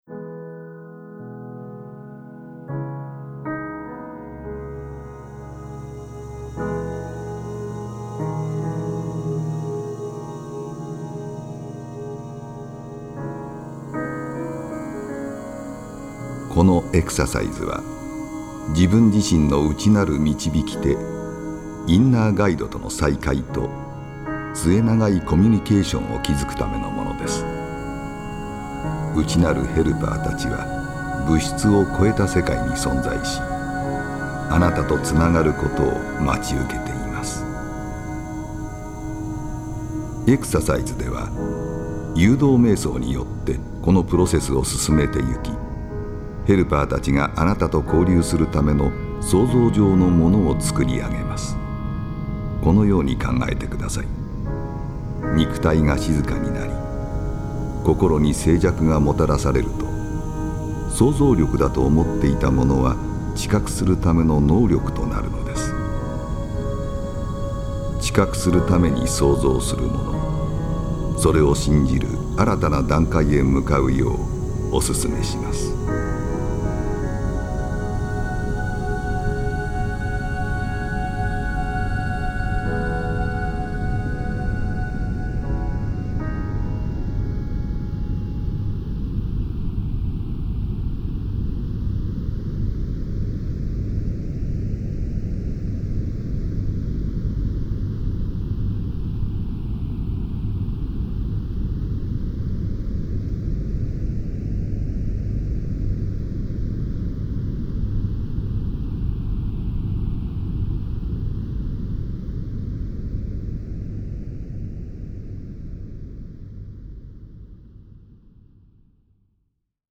このオーディオ・ガイダンスのプロセスは、何層もの複雑なオーディオ信号を組み合わせることによって共鳴現象を起こし、特殊な脳波を発生させて、意識を特別な状態へと誘導するものです。
ヘミシンク信号に加えて、ミュージックや音声ガイダンス、あるいはかすかな音響効果などが組み合わされて、その効果はさらに高められます。